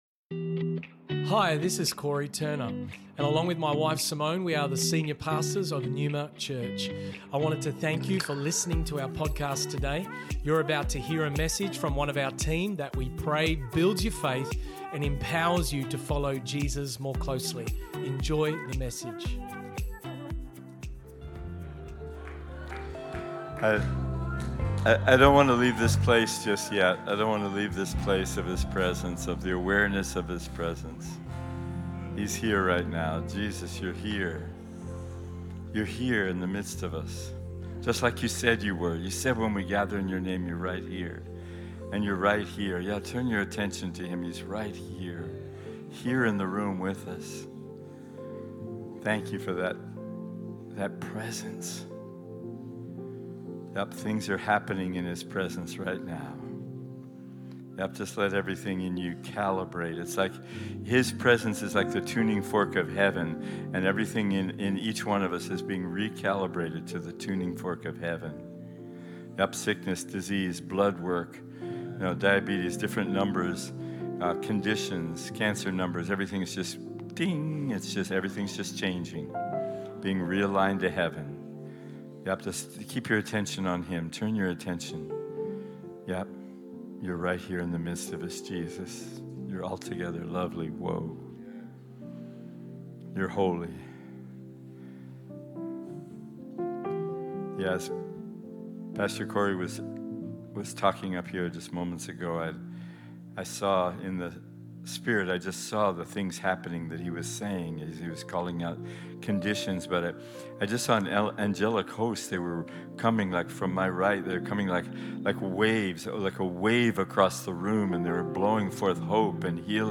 Neuma Church Originally Recorded at the 11:30AM revival service on the 2nd April, 2023&nbsp